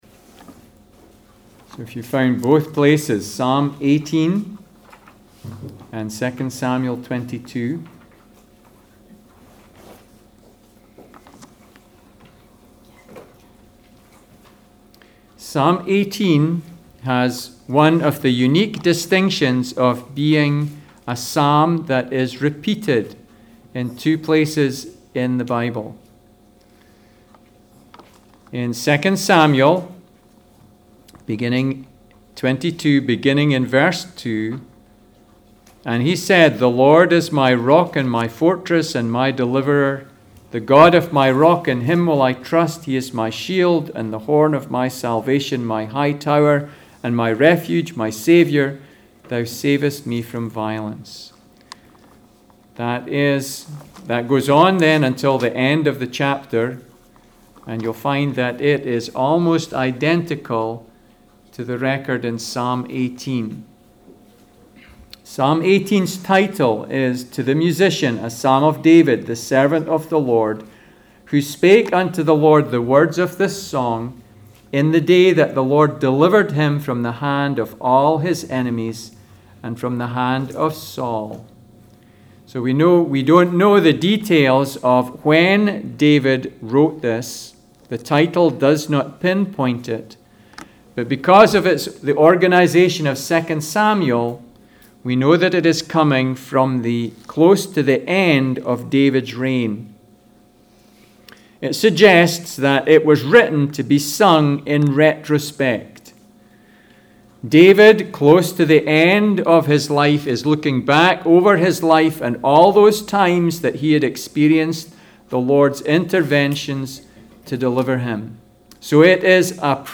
Passage: Psalm 18 Service Type: Sunday Service